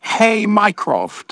synthetic-wakewords
synthetic-wakewords / hey_mycroft /ovos-tts-plugin-deepponies_Discord_en.wav
ovos-tts-plugin-deepponies_Discord_en.wav